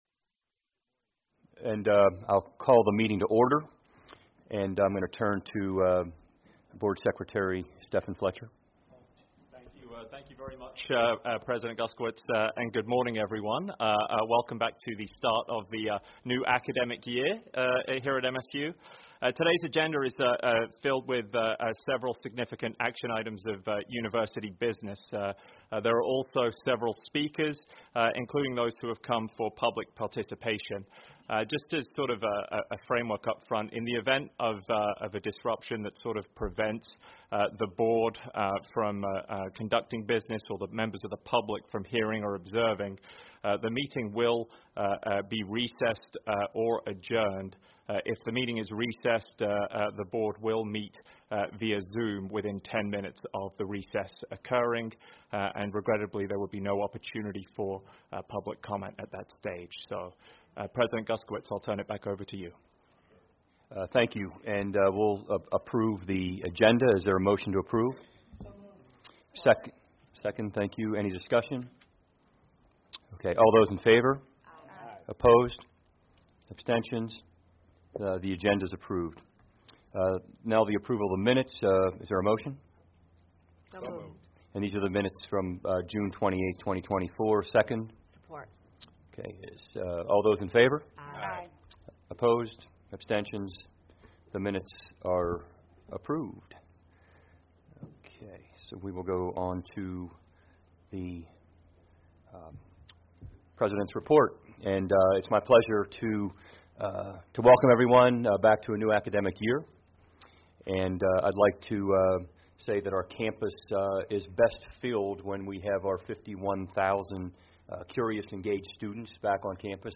Where: Lincoln Room, Kellogg Center
Board Meeting